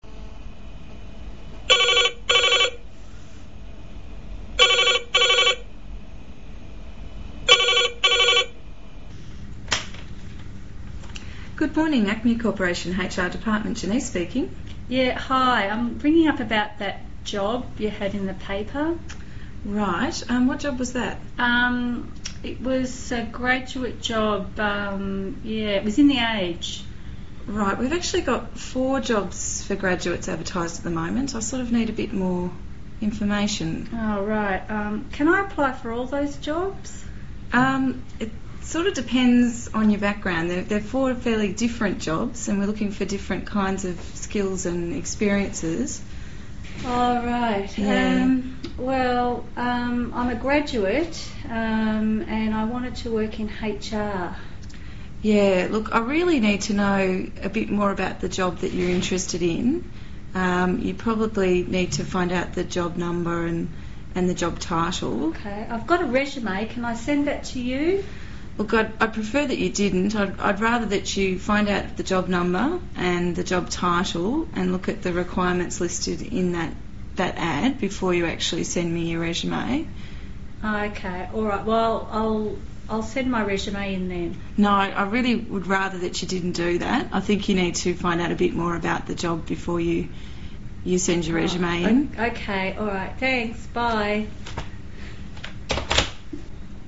Listening to conversations